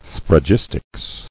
[sphra·gis·tics]